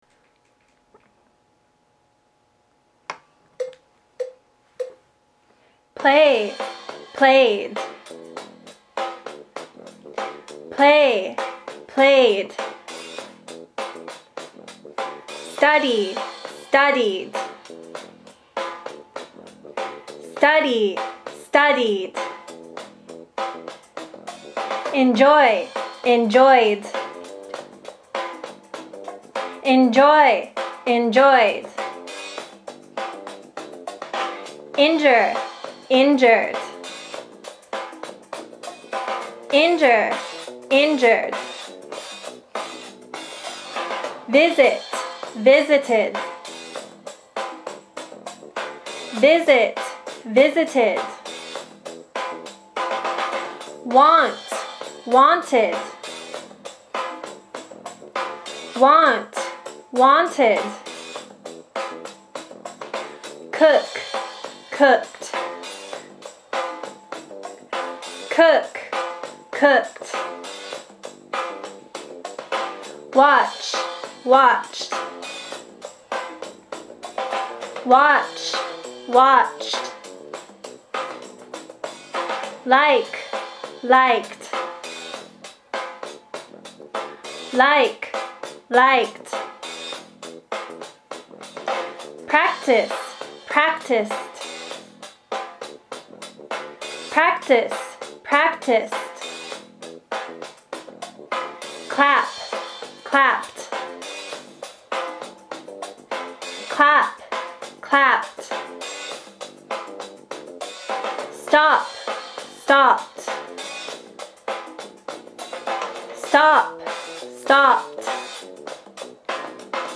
プリントを見ながら現在形と過去形を順番にリズムに合わせてリピートし， 書く練習をしておきましょう。 ２年英語 過去形２５種 ２年英語 過去形リピート練習用音声データ